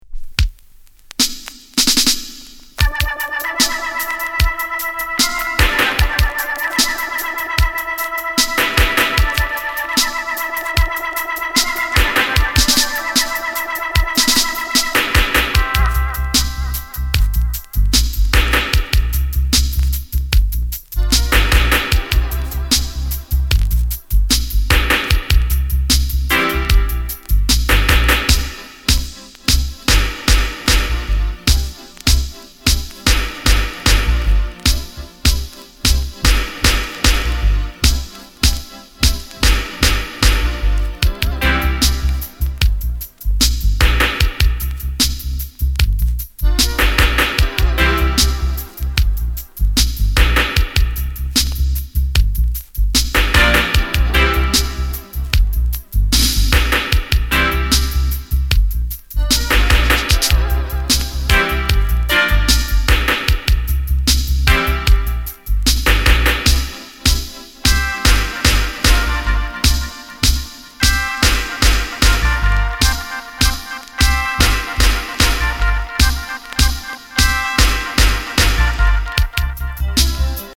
Genre: Reggae/Dancehall